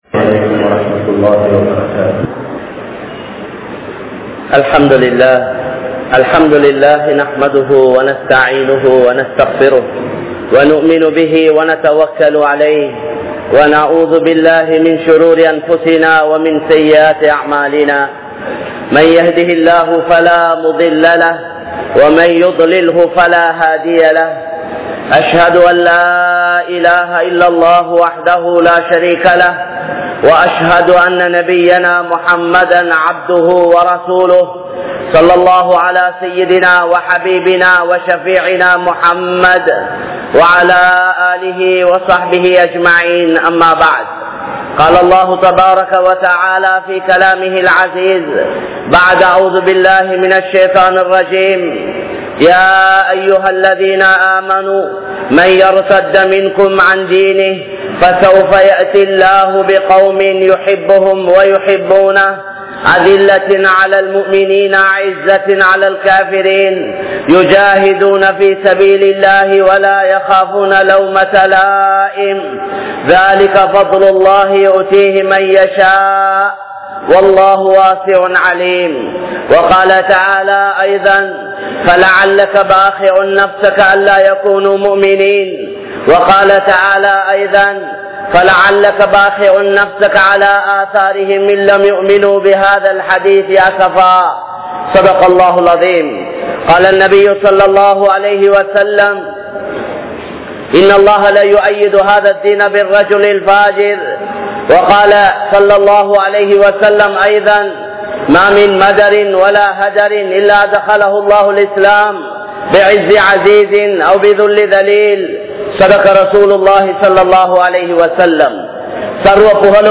Ungalin Noakkam Enna? | Audio Bayans | All Ceylon Muslim Youth Community | Addalaichenai